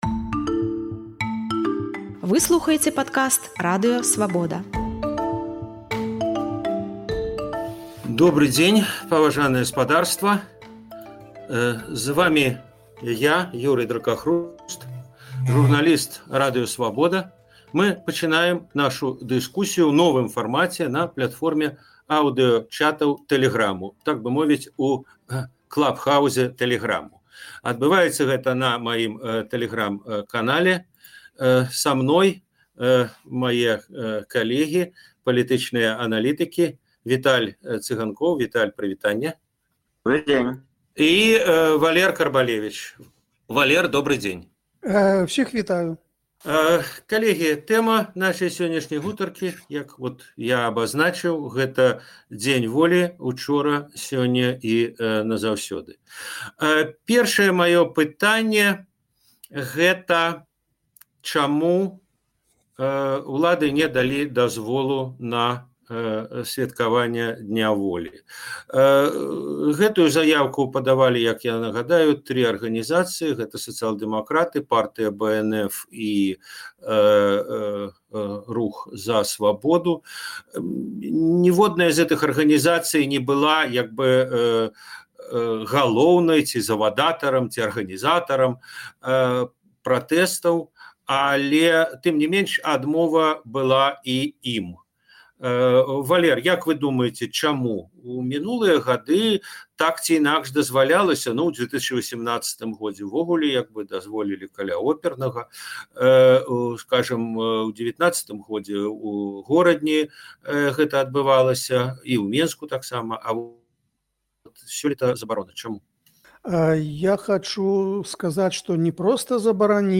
Гэтыя тэмы ў Праскім акцэнце абмяркоўваюць палітычныя аналітыкі